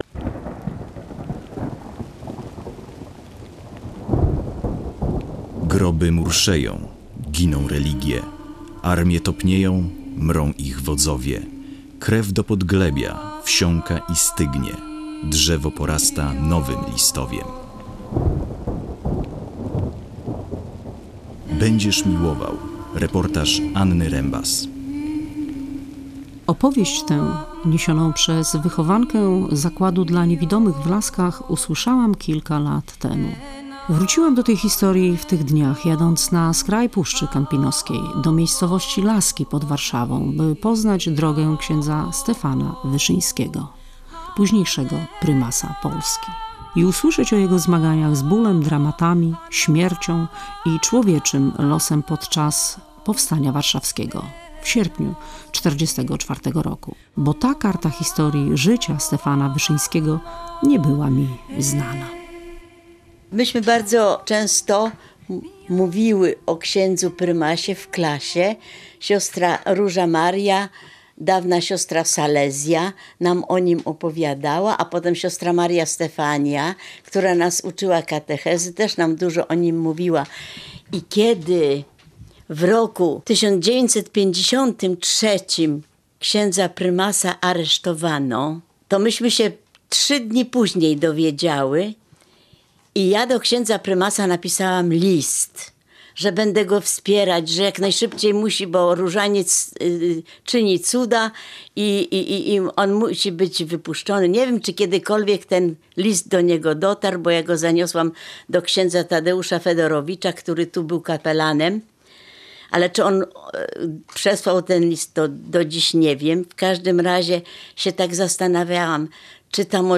„Będziesz miłował”. Reportaż o losach prymasa Wyszyńskiego podczas powstania warszawskiego